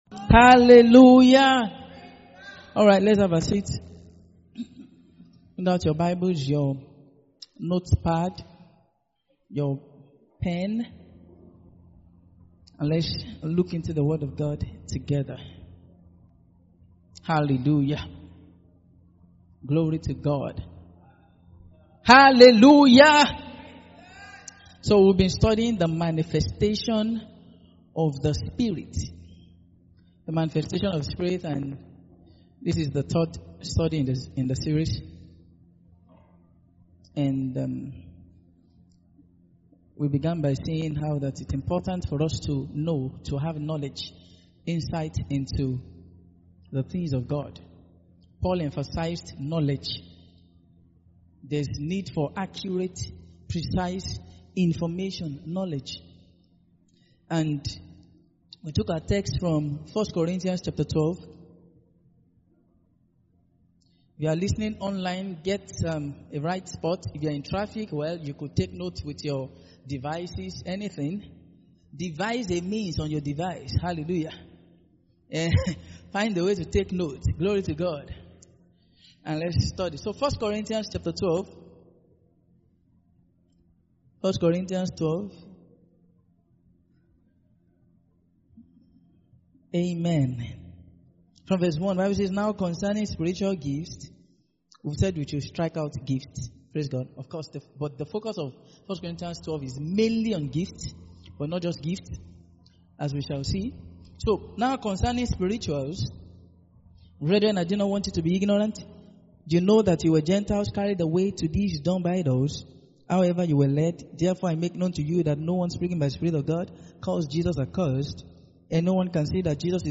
Mid-Week Sermons